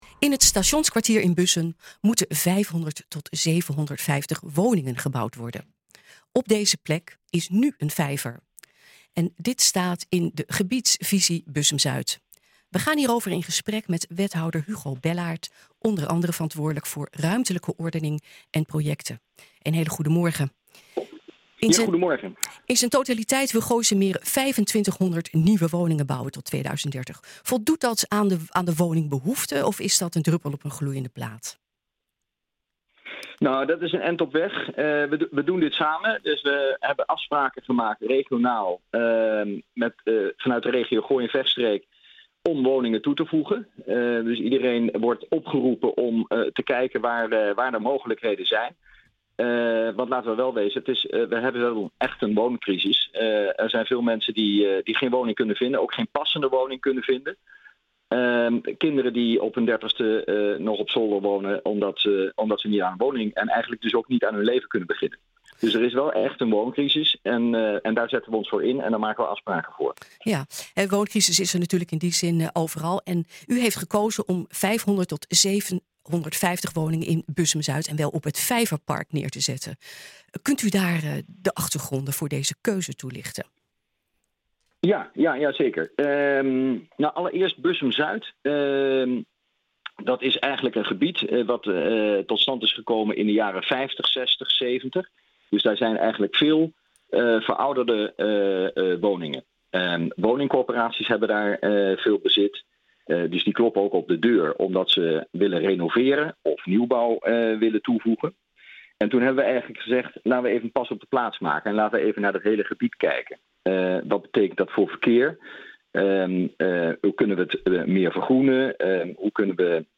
Dit staat in de Gebiedsvisie Bussum-Zuid. We gaan hierover in gesprek met wethouder Hugo Bellaart, onder andere verantwoordelijk voor ‘Ruimtelijke ordening en projecten’.
nh-gooi-zaterdag-wethouder-hugo-bellaart-over-woningen-plek-vijver-bussum.mp3